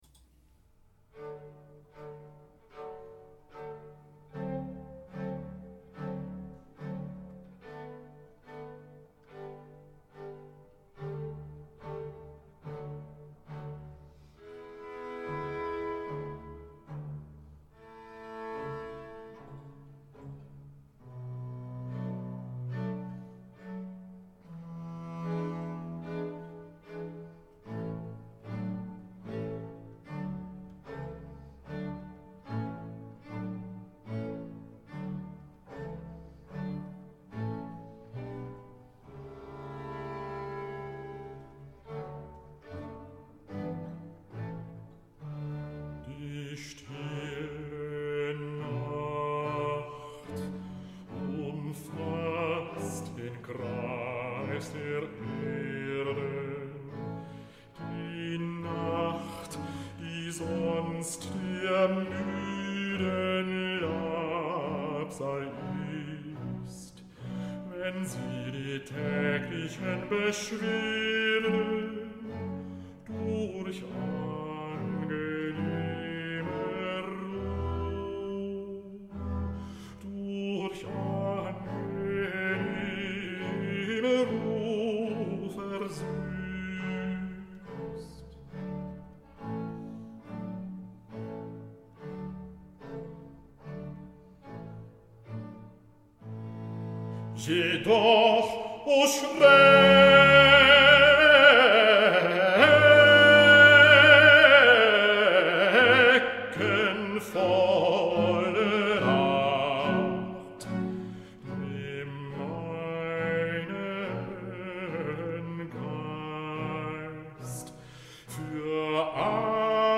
Opening op deze Witte Donderdag met muziek, rechtstreeks vanuit onze studio.
vier (van de zes) delen van een cantate voor bas-solo